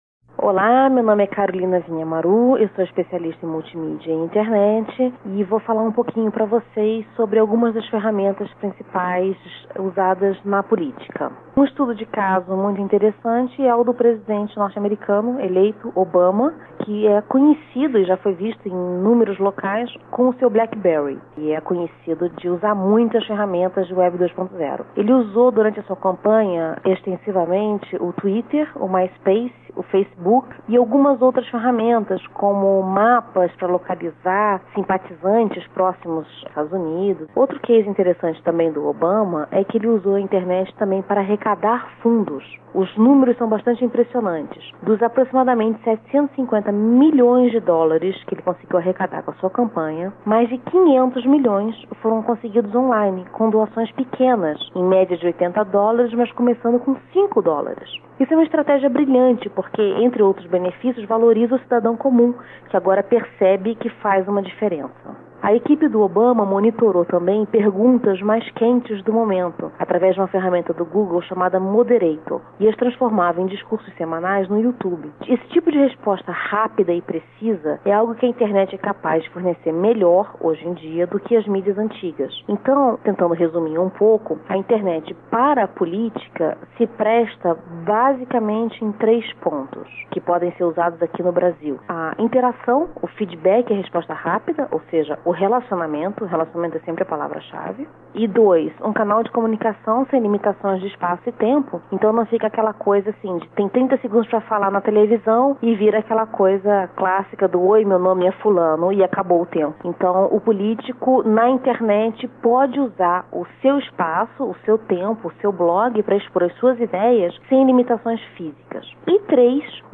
entrevista à Folha 12/02/2009